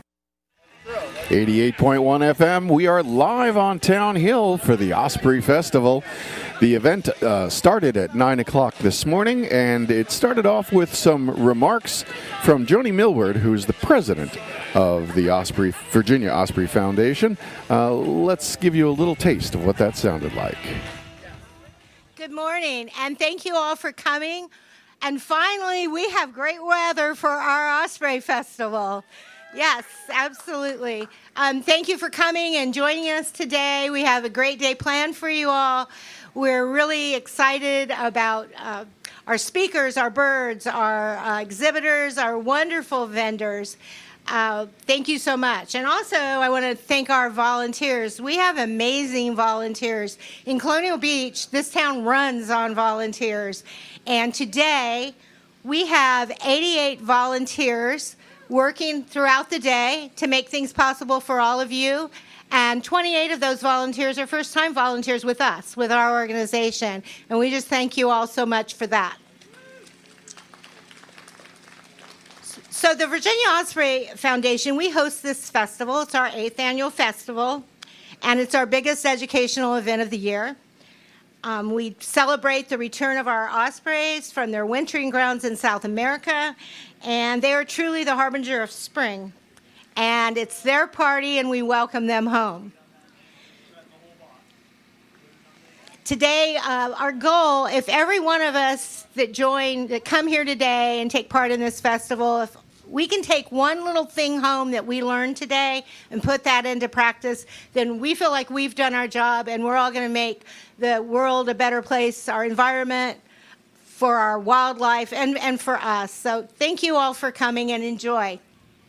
Osprey Festival Opening remarks